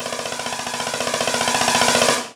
snarefill1.ogg